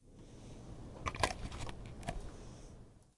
描述：特写在Meyer图书馆插上笔记本充电器
Tag: 鼠标 笔记本电脑 迈尔 插头 插座 斯坦福 斯坦福大学大学